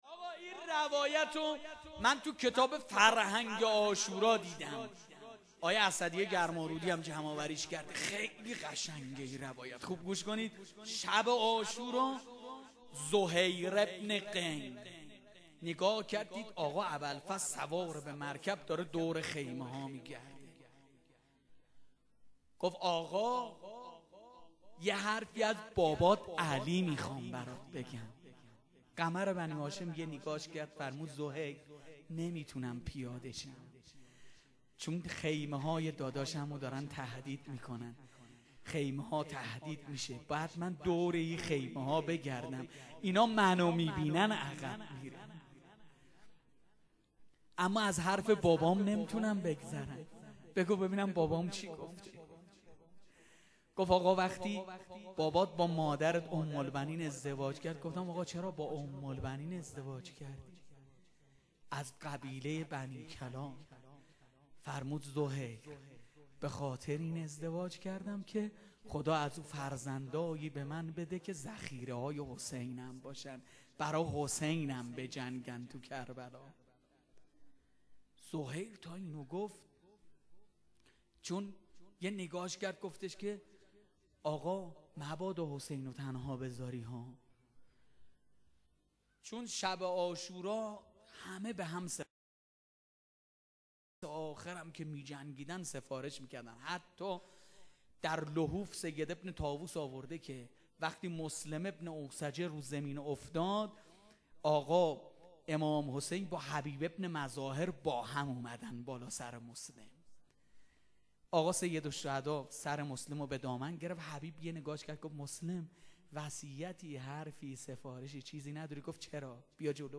روضه حضرت ابالفضل قسمت 2 -قزوین-آستان مقدس چهارانبیا-موسسه پرچمدار.mp3